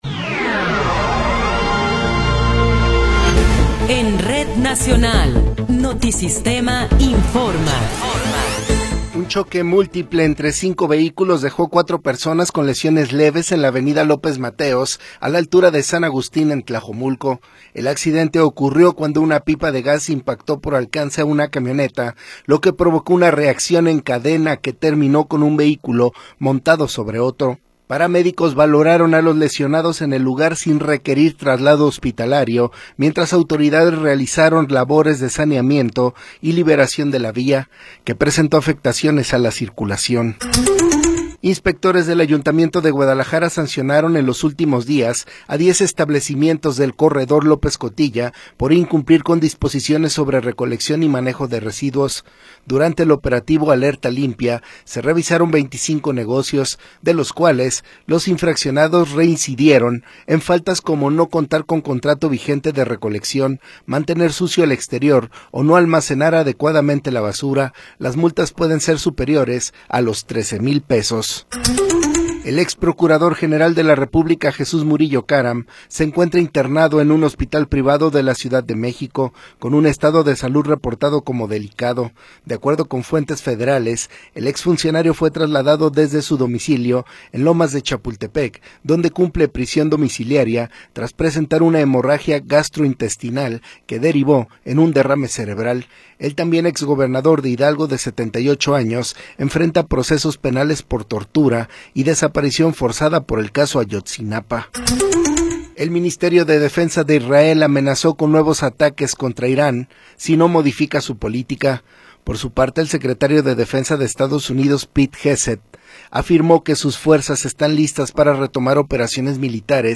Noticiero 13 hrs. – 18 de Abril de 2026